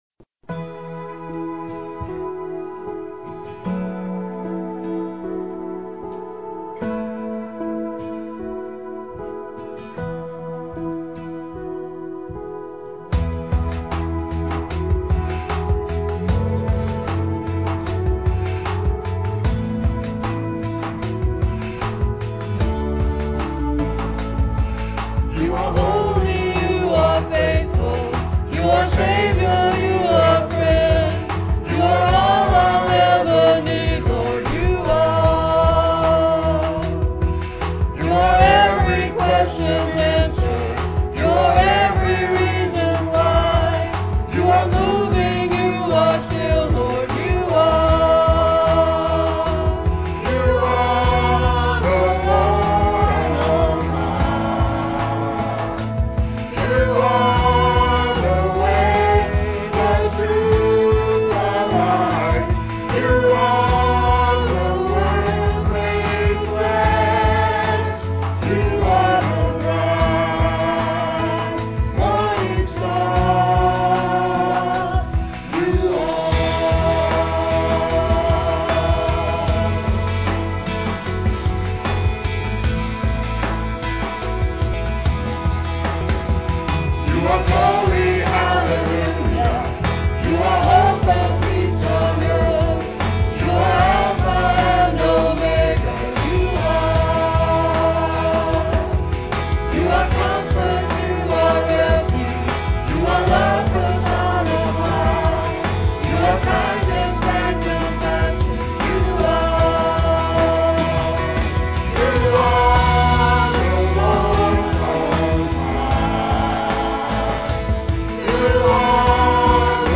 PLAY Prophecy Series, Part 4, Oct 1, 2006 Scripture: Matthew 24:32-44. Scripture Reading